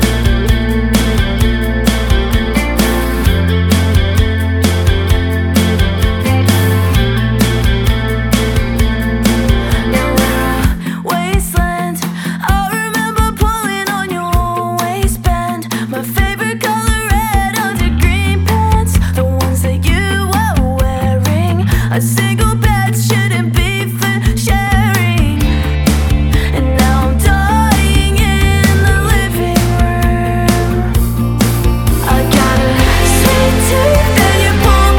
Жанр: Рок
# Rock